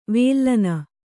♪ vēllana